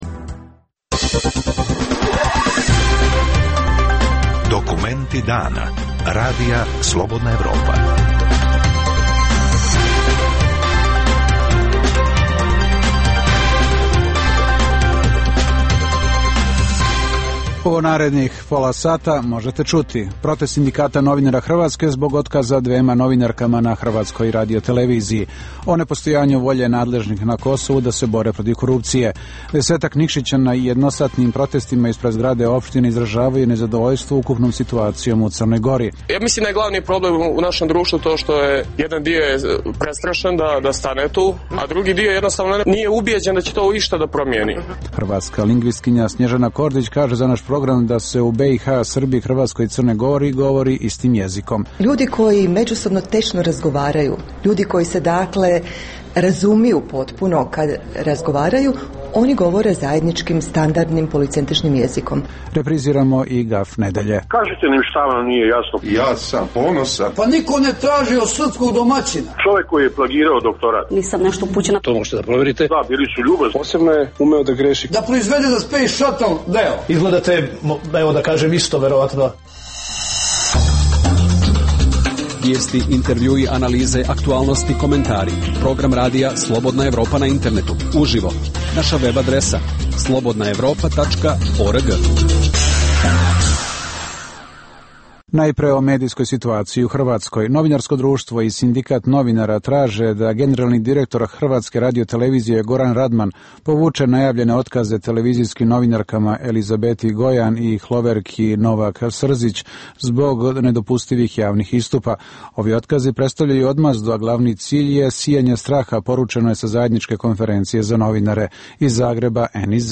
Dnevna emisija u kojoj dublje istražujemo aktuelne događaje koji nisu u prvom planu kroz intervjue, analize, komentare i reportaže.